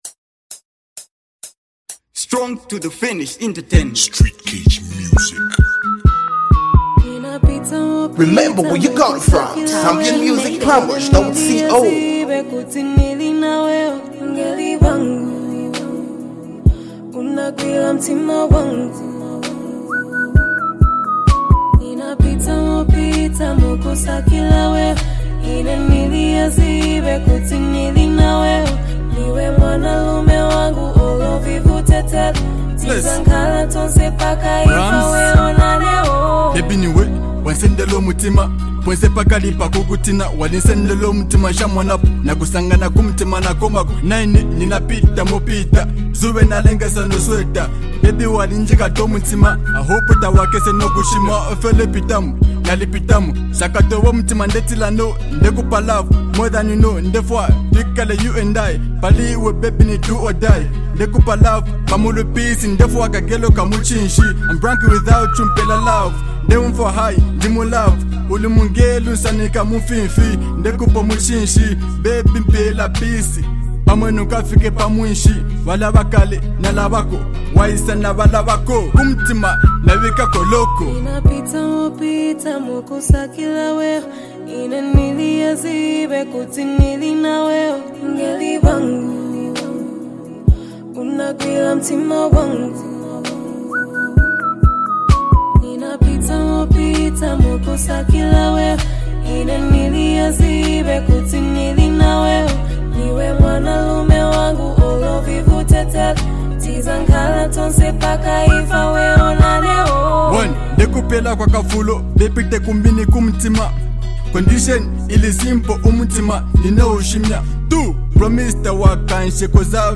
With its soulful melodies and powerful lyrics
smooth vocals